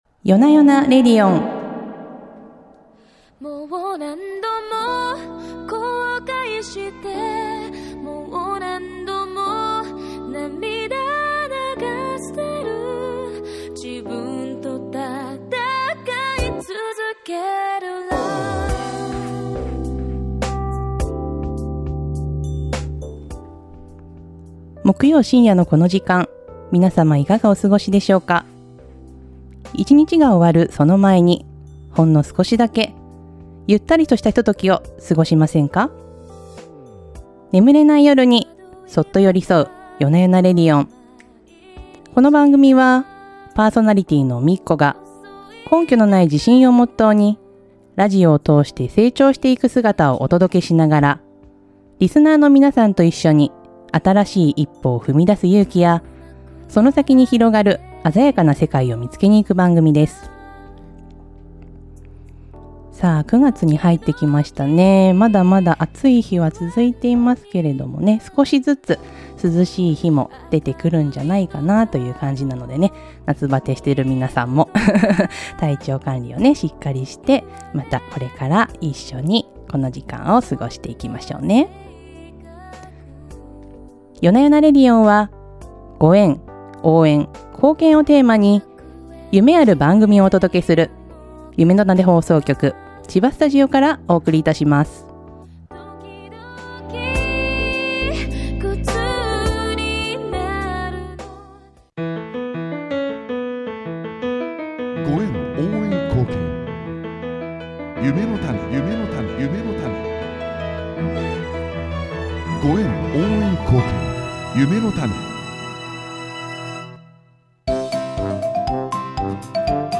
時にはひとりで、時には誰かと一緒に、気ままなお喋りを繰り広げながら、あなたの枕元に寄り添います。